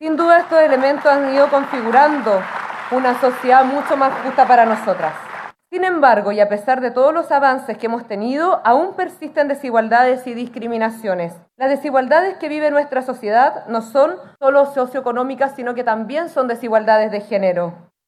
Así lo señalaron ambas autoridades de Gobierno en medio de una actividad realizada en la comuna de Quinta Normal.